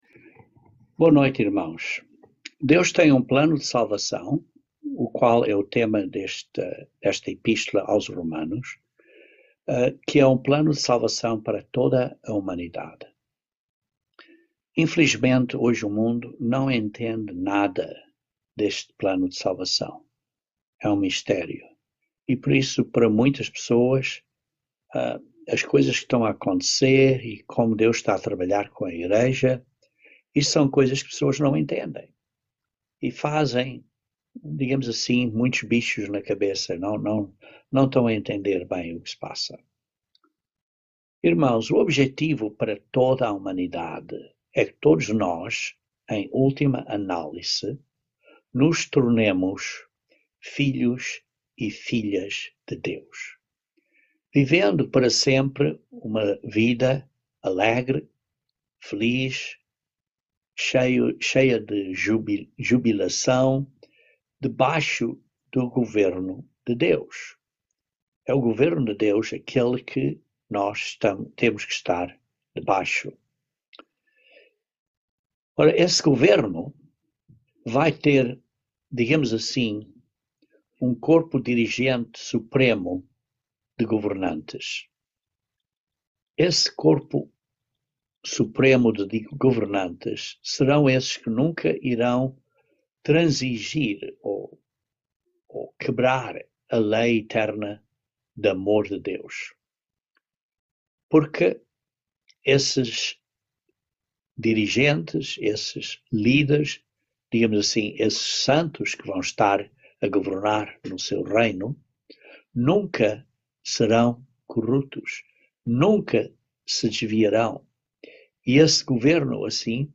Given in Patos de Minas, MG